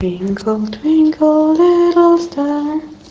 Tag: 歌曲 女性 女人